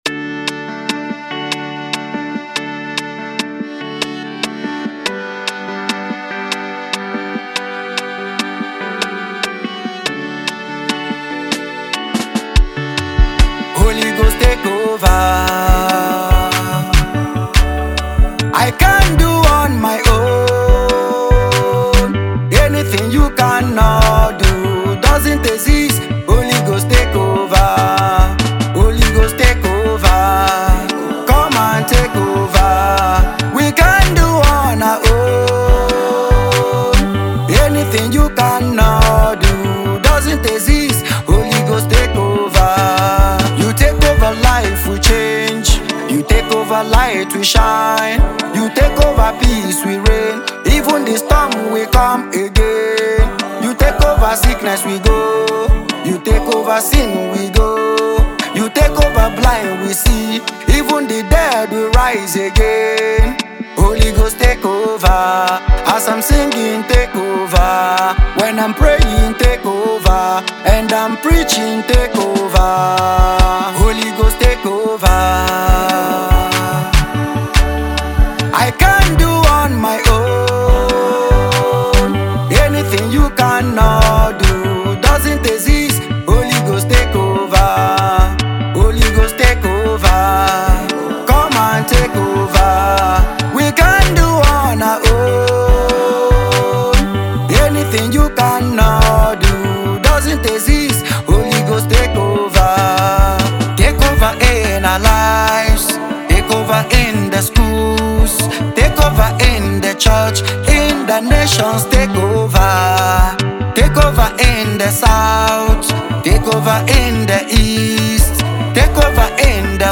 gospel songwriter and singer